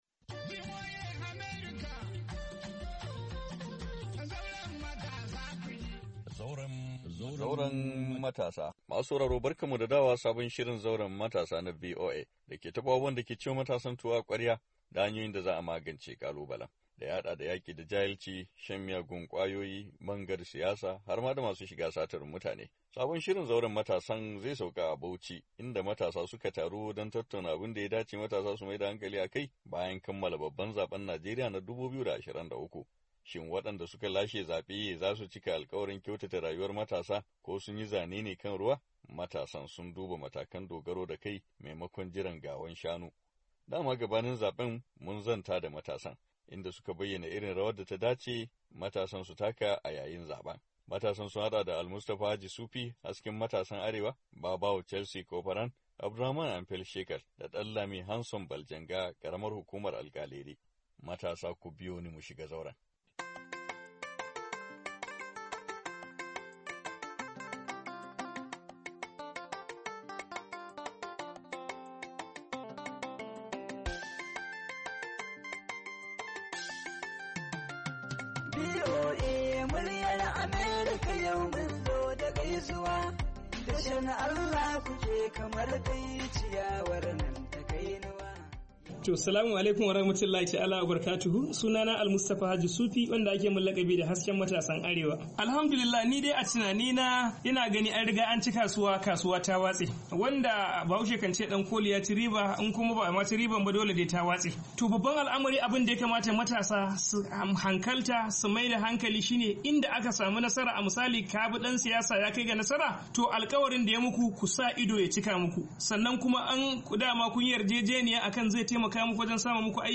ABUJA, NIGERIA - A cikin shirin na wannan makon mun sauka a Bauchi inda matasa su ka taru don tattauna abun da ya dace matasa su maida hankali a kai bayan kammala babban zaben Najeriya na 2023.